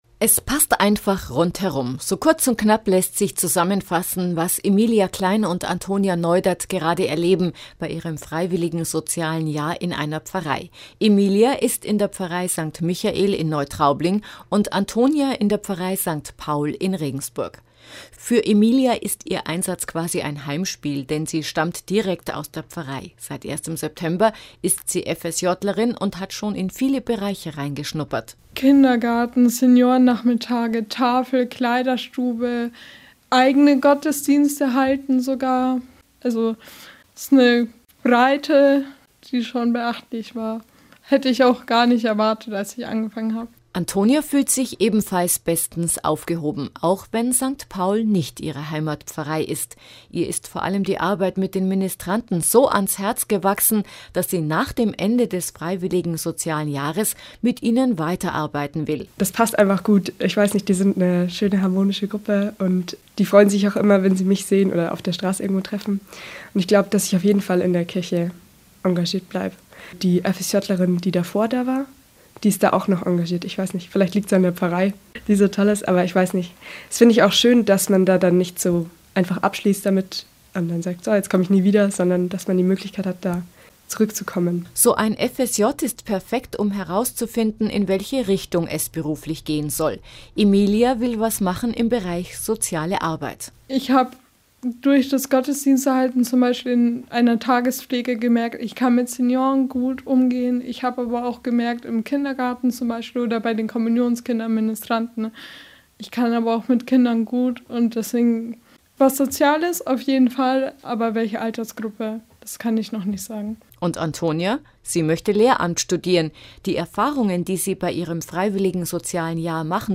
FSJ im Interview